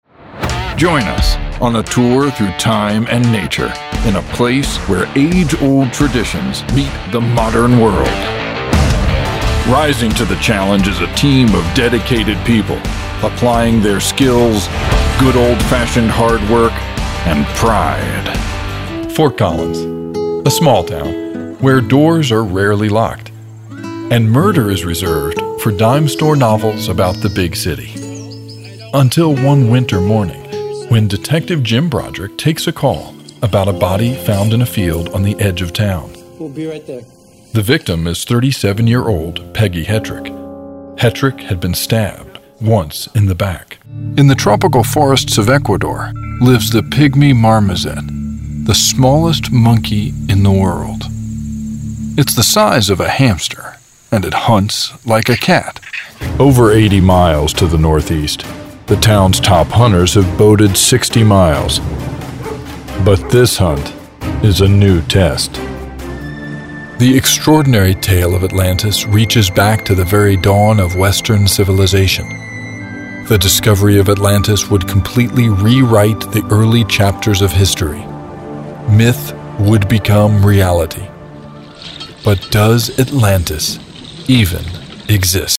Bourbon and blue jeans, a smooth, comfortable, confident voiceover.
English - USA and Canada
Middle Aged